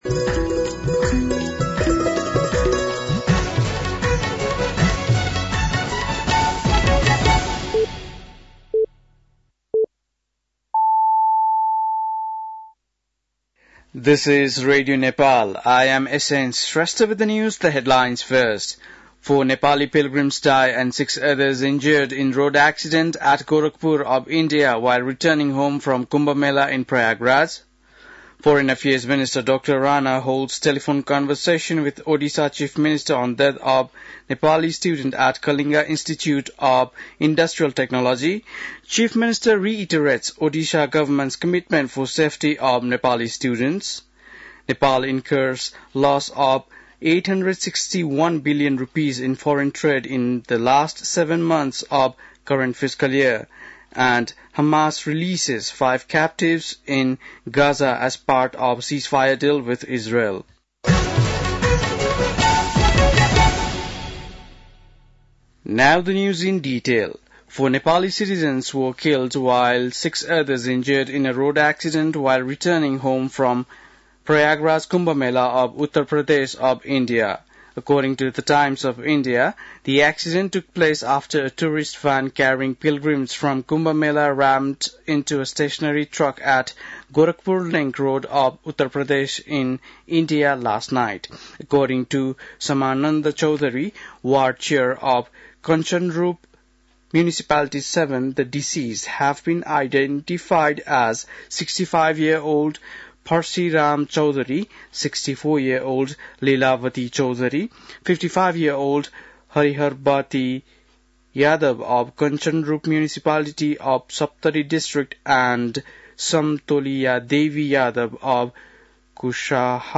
बेलुकी ८ बजेको अङ्ग्रेजी समाचार : ११ फागुन , २०८१